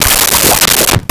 Swirl 03
Swirl 03.wav